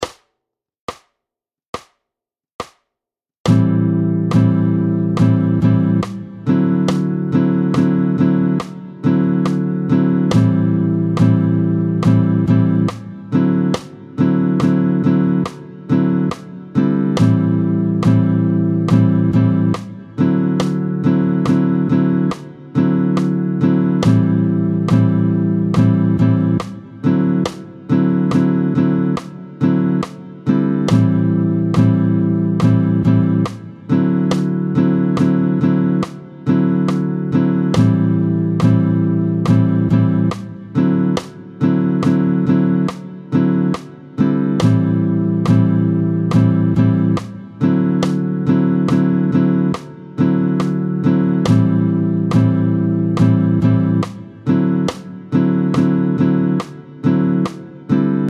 Anticiper l’accord de RÉ9, c’est le jouer sur le 4ème contretemps de la première mesure alors qu’il devrait se placer sur le premier temps de la mesure suivante.
30-01 Lam7 / Ré9, tempo 70